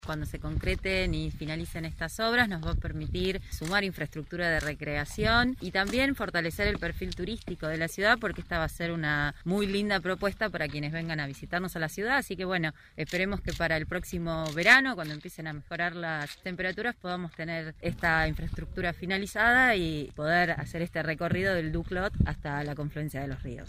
María Victoria Fernández, subsecretaria de Vinculación Ciudadana.